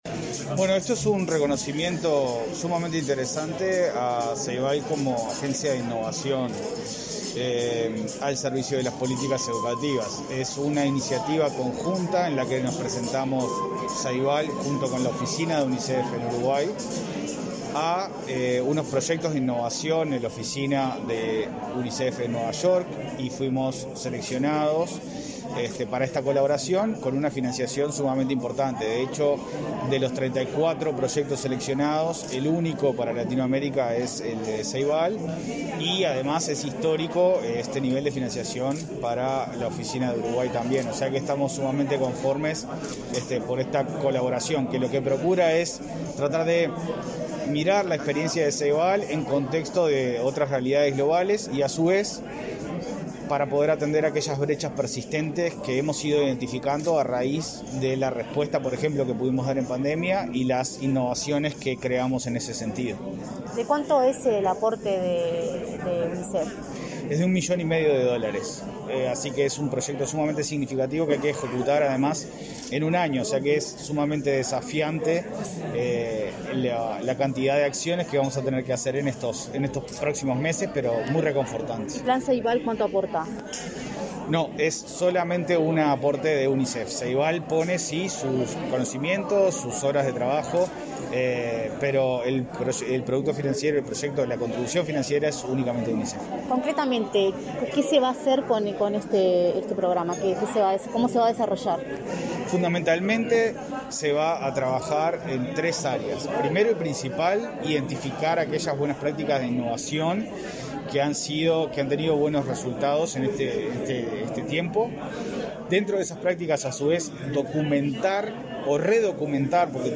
Entrevista al director del Plan Ceibal, Leandro Folgar